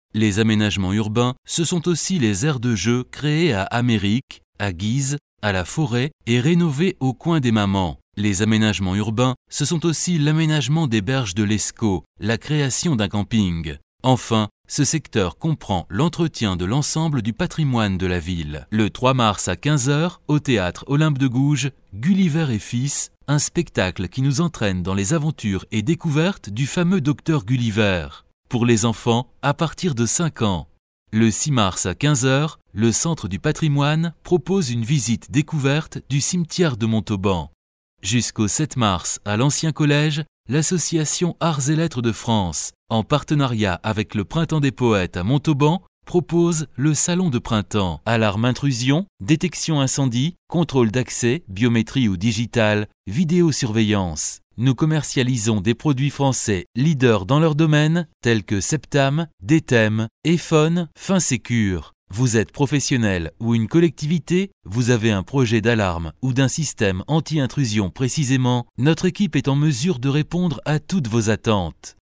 voix medium, dynamique, posée, jouée pour publicités ou commentaires, e learning, attentes téléphoniques ou tout autre travail audio station protools micro B1
Sprechprobe: Industrie (Muttersprache):